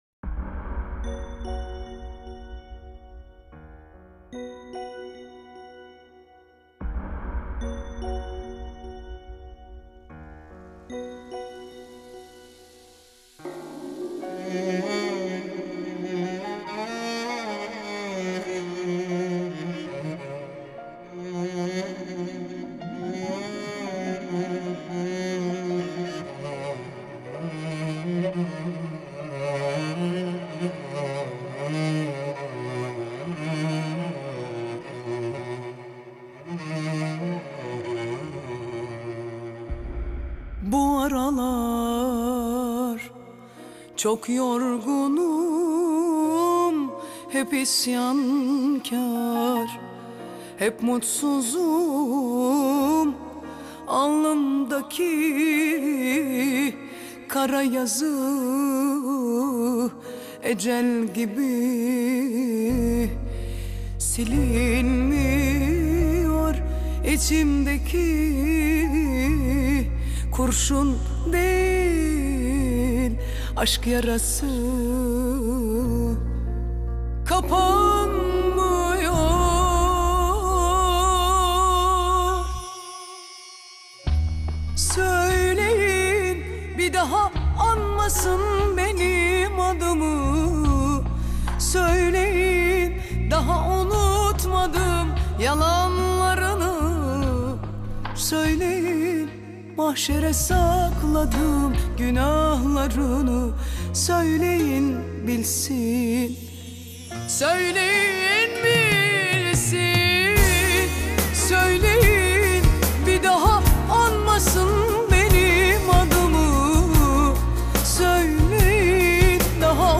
خواننده زن